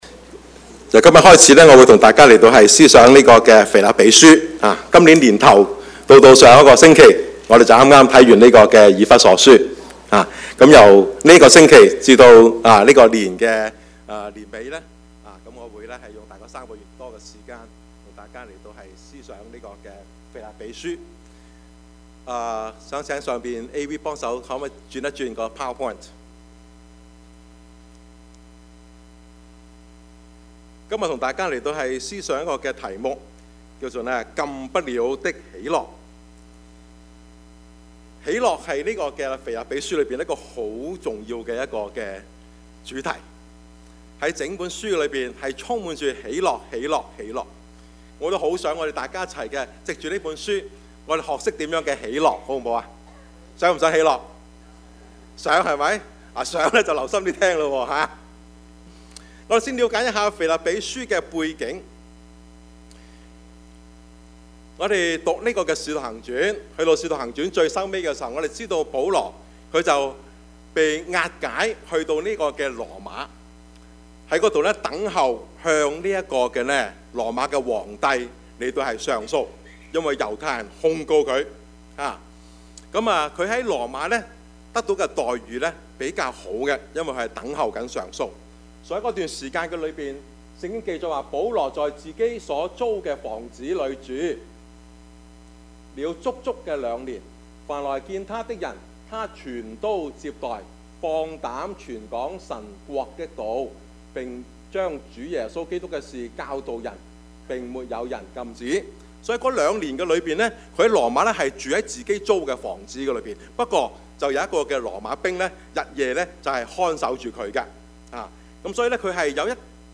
Passage: 腓立比書 1:12-20 Service Type: 主日崇拜
Topics: 主日證道 « 屬靈的爭戰 應當一無掛慮 »